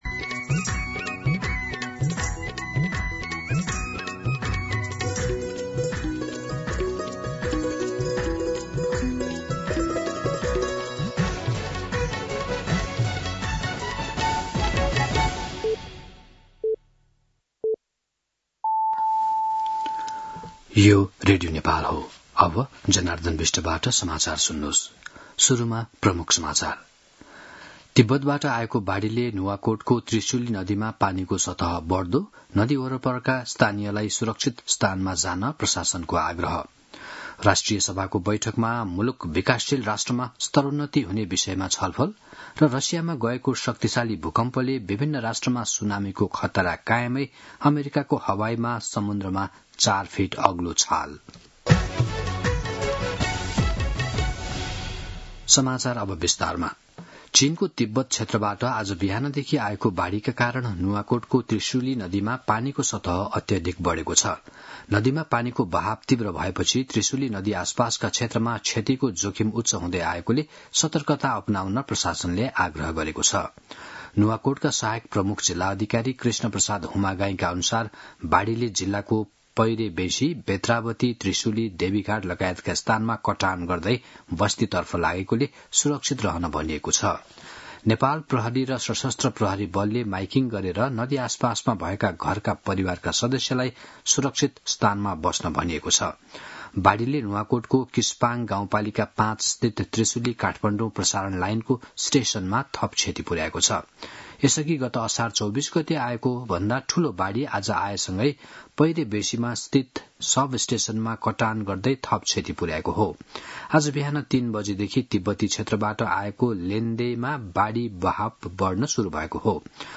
दिउँसो ३ बजेको नेपाली समाचार : १४ साउन , २०८२
3pm-News-04-14.mp3